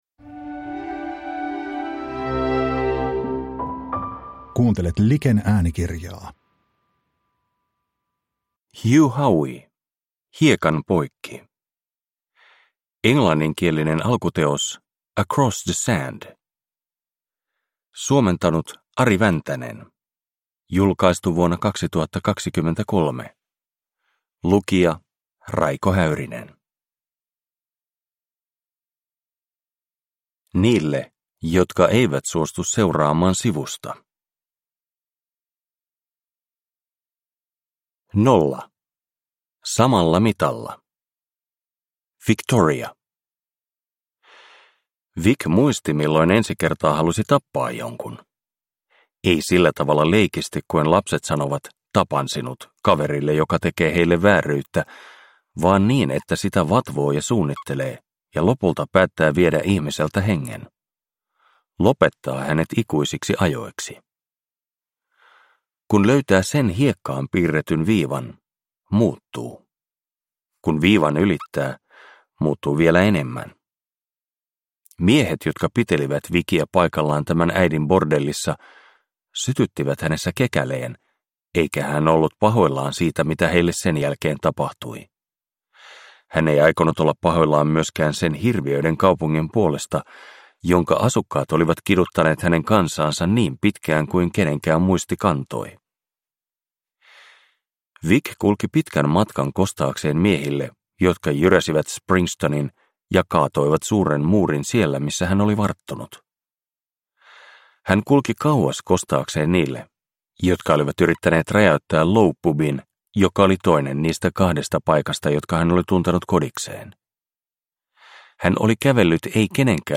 Hiekan poikki – Ljudbok – Laddas ner